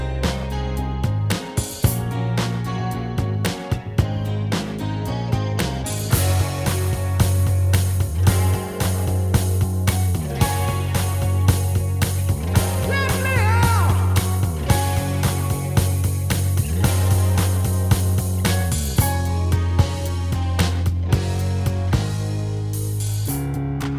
Minus Guitars Pop